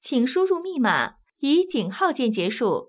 ivr-please_enter_pin_followed_by_pound.wav